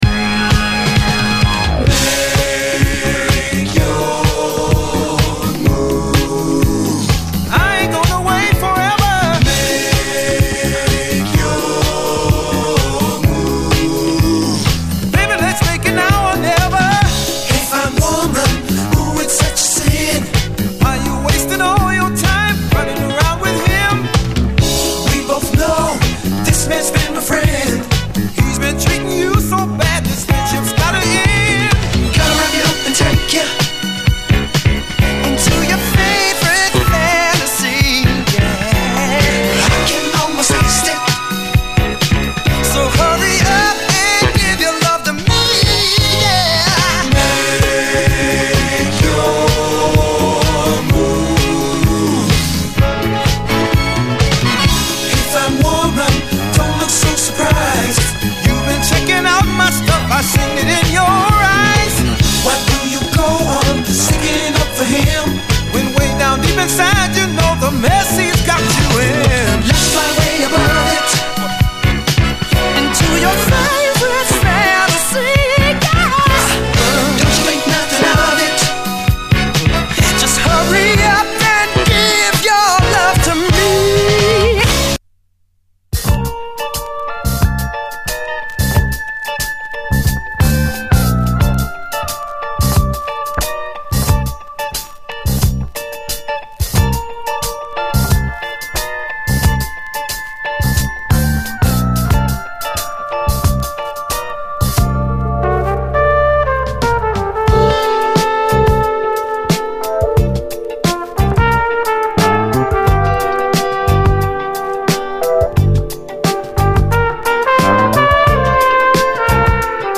SOUL, 70's～ SOUL, DISCO
黒いグルーヴをたたえたソウルフル・ブギー